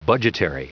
Prononciation du mot budgetary en anglais (fichier audio)
Prononciation du mot : budgetary